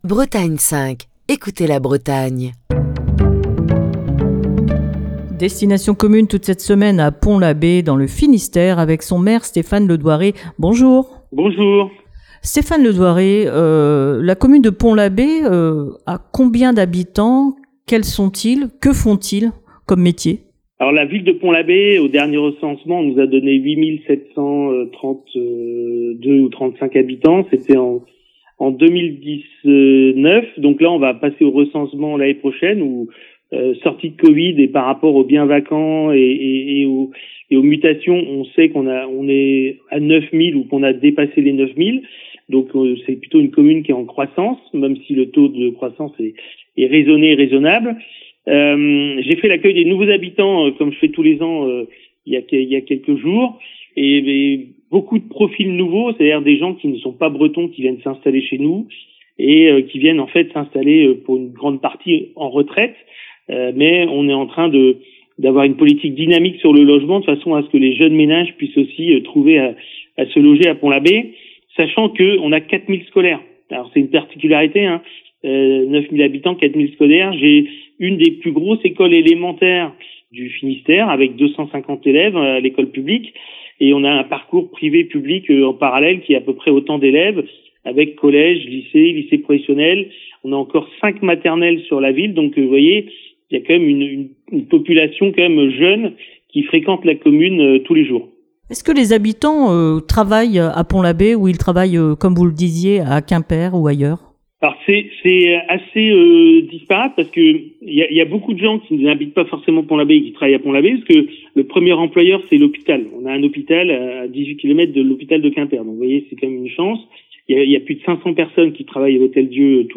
maire de Pont-l'Abbé dans le Finistère, où Destination Commune a posé ses micros.